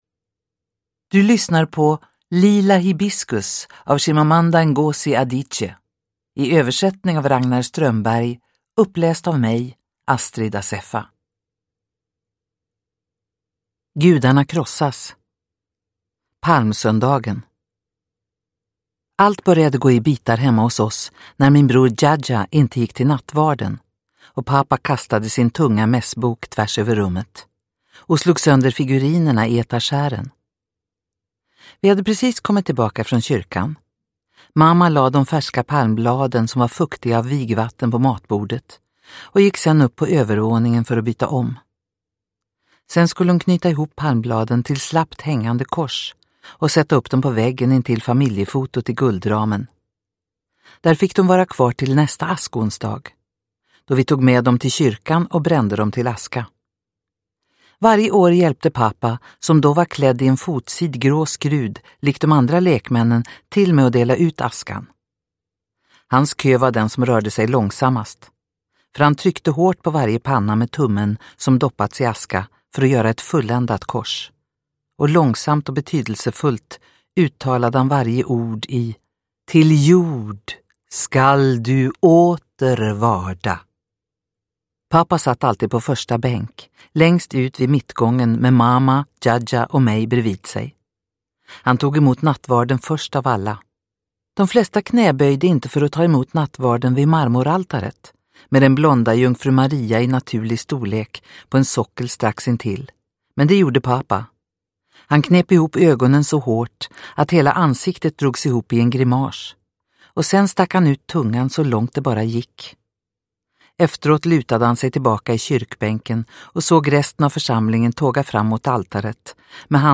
Lila hibiskus – Ljudbok – Laddas ner